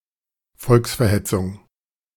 Volksverhetzung (German: [ˈfɔlksfɐˌhɛtsʊŋ]
De-Volksverhetzung.ogg.mp3